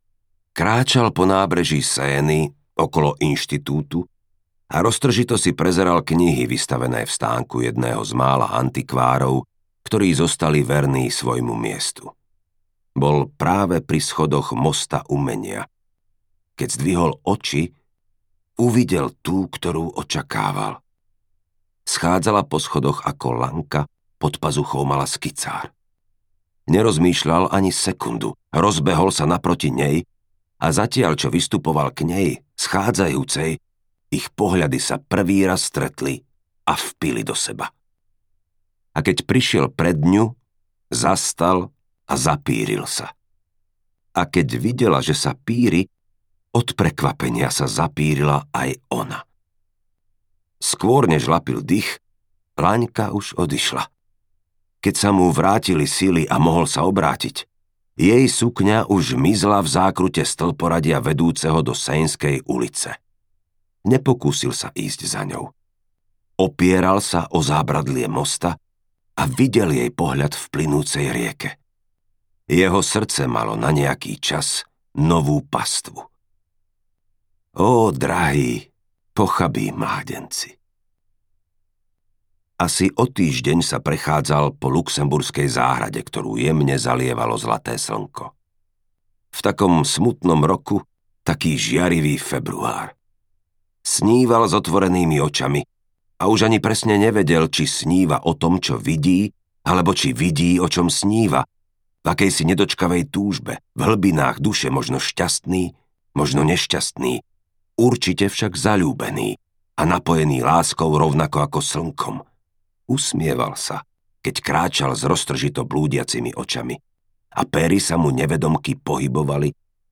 Peter a Lucia audiokniha
Ukázka z knihy